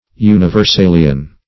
Meaning of universalian. universalian synonyms, pronunciation, spelling and more from Free Dictionary.
Search Result for " universalian" : The Collaborative International Dictionary of English v.0.48: Universalian \U`ni*ver*sa"li*an\, a. Of or pertaining to Universalism; Universalist.